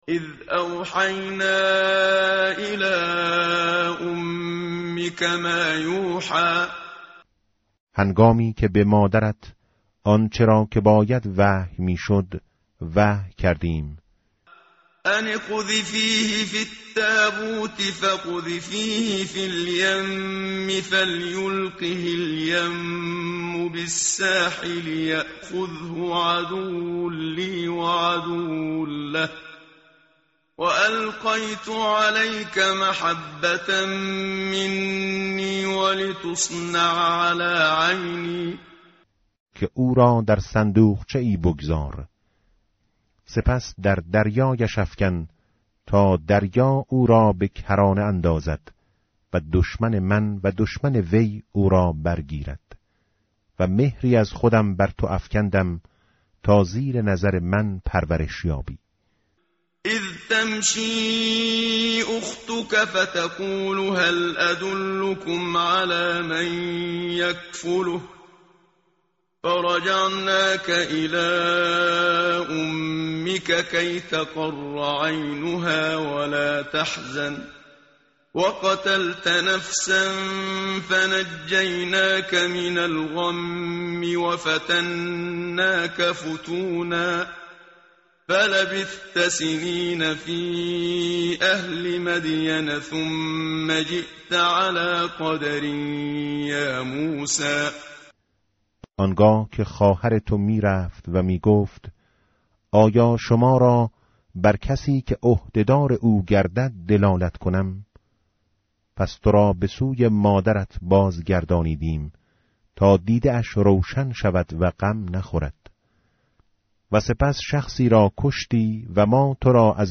tartil_menshavi va tarjome_Page_314.mp3